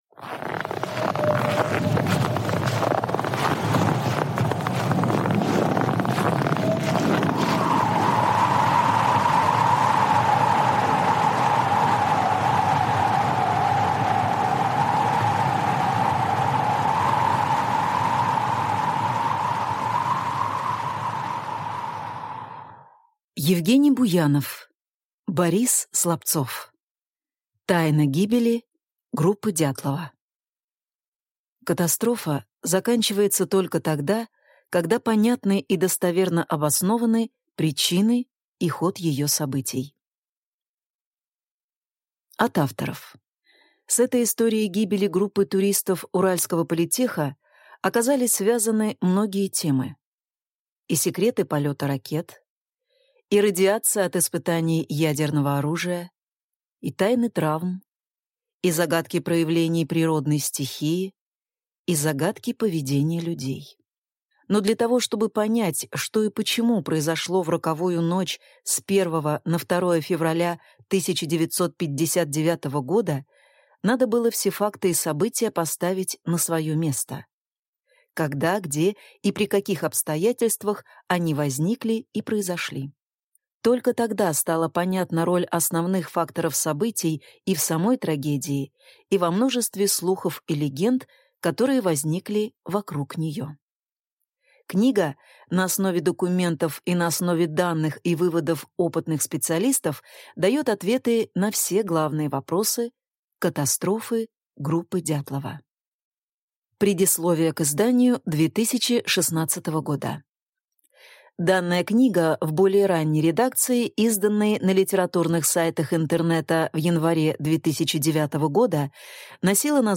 Аудиокнига Тайна гибели группы Дятлова | Библиотека аудиокниг